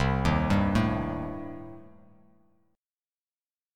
Cm6 Chord
Listen to Cm6 strummed